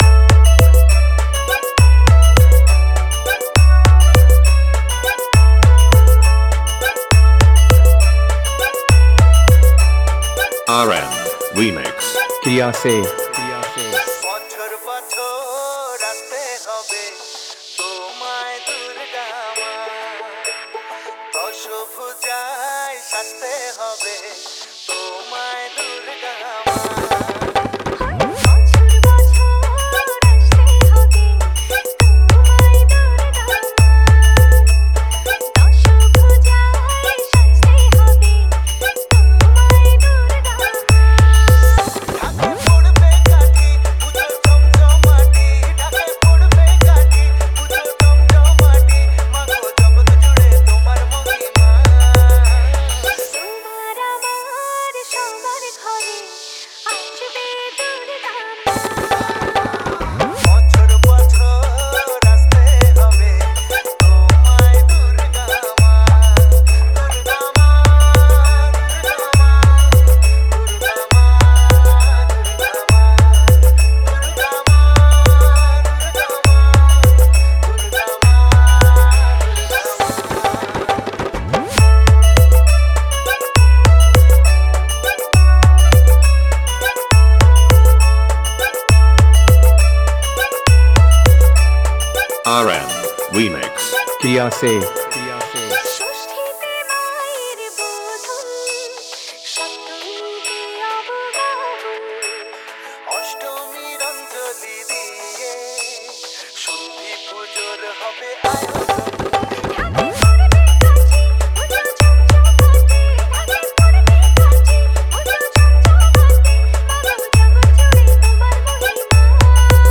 দুর্গ উৎসব স্পেশাল বাংলা নতুন স্টাইল ভক্তি হামবিং মিক্স 2024